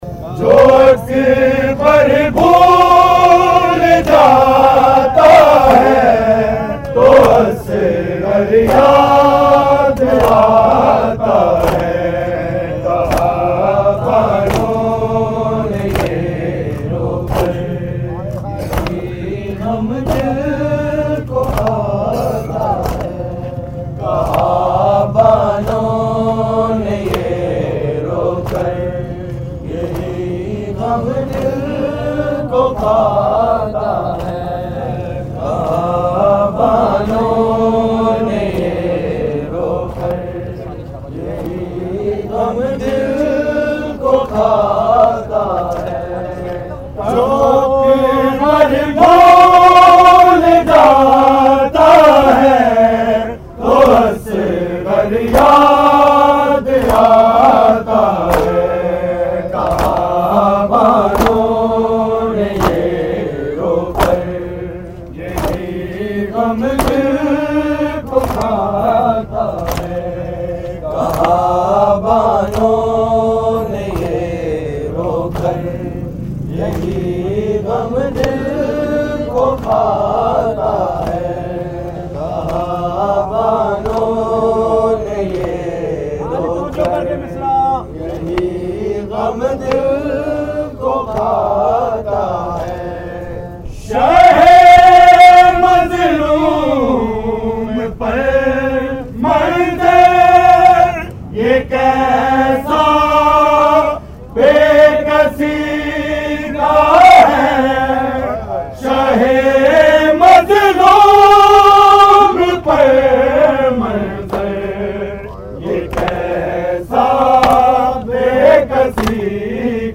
Recording Type: Live